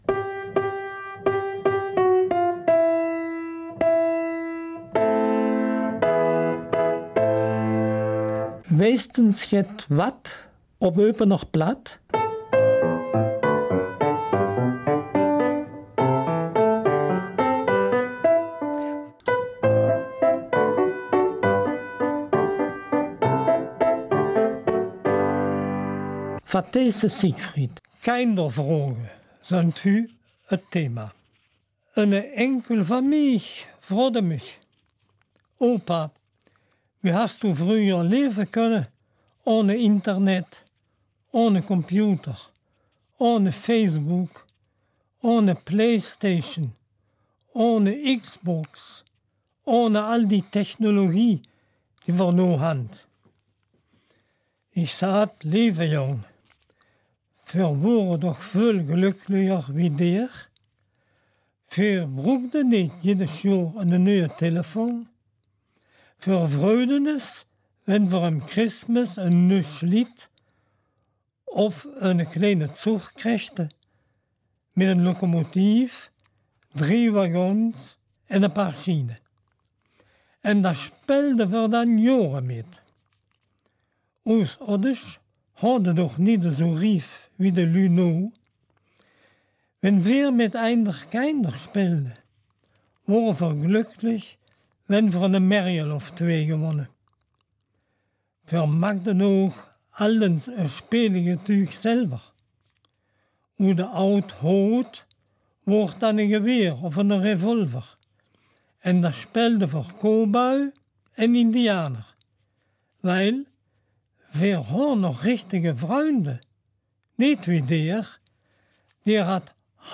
Eupener Mundart - 9. August